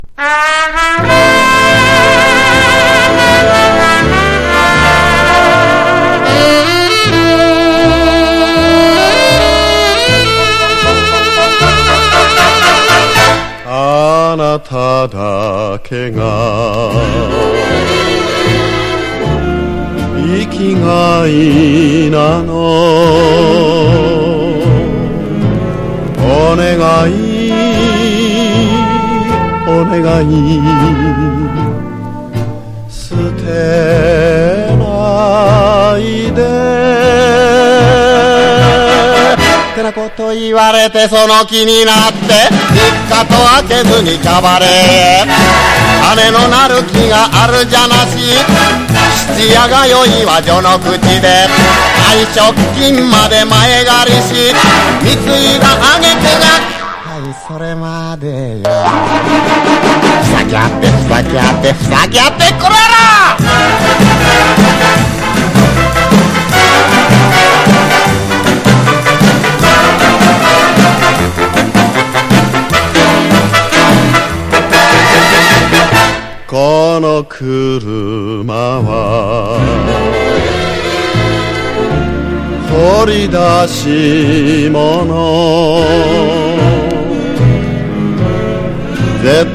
和モノ / ポピュラー
所によりノイズありますが、リスニング用としては問題く、中古盤として標準的なコンディション。